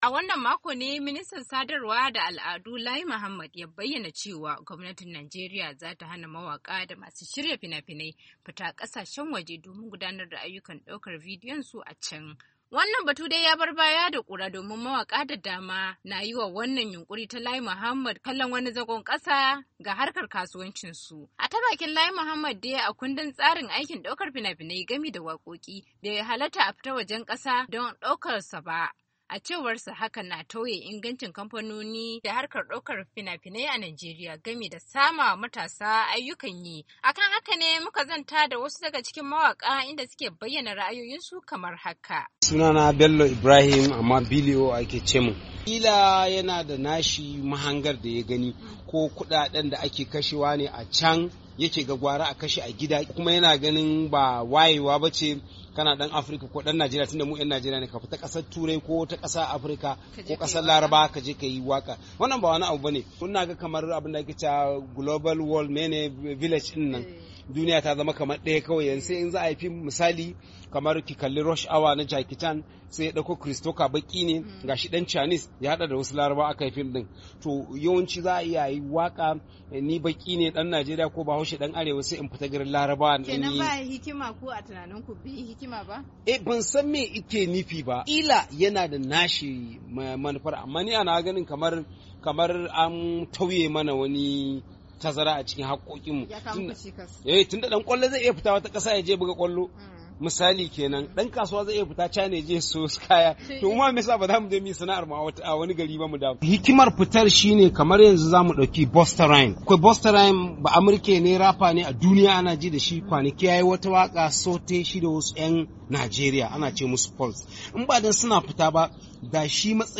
A kan haka ne muka zanta da wasu daga cikin mawakan inda suke bayyana ra’ayoyinsu kamar daban daban